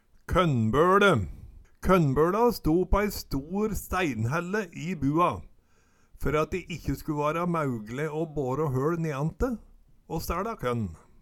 kønnbøLe - Numedalsmål (en-US)
Høyr på uttala Ordklasse: Substantiv hokjønn Kategori: Hushald, mat, drikke Bygning og innreiing Jordbruk og seterbruk Attende til søk